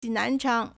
南昌 nánchāng
nan2chang1.mp3